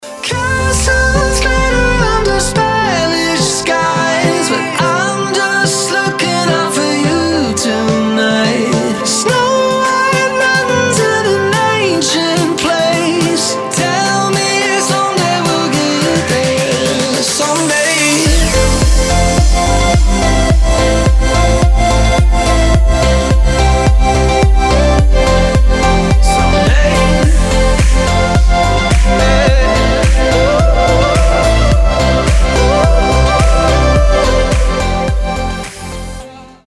• Качество: 320, Stereo
поп
мужской вокал
красивые
dance
Electronic
лиричные